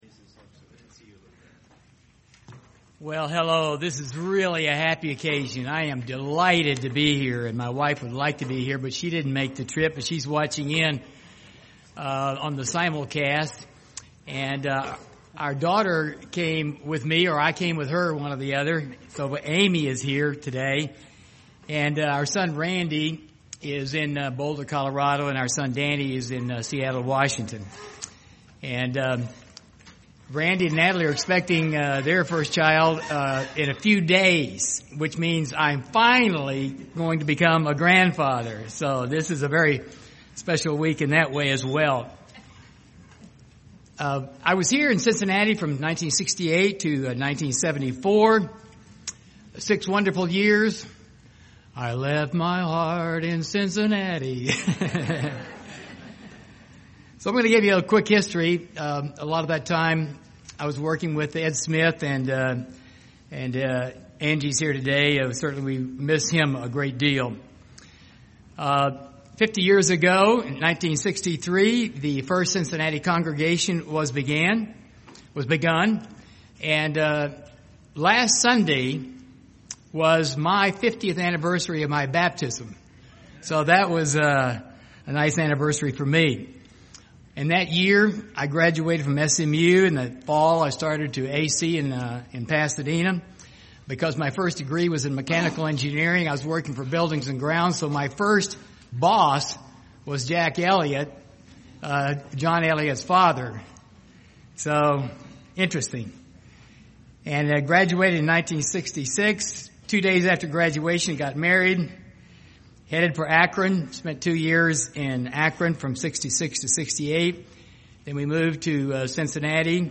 Given in Cincinnati East, OH
UCG Sermon Studying the bible?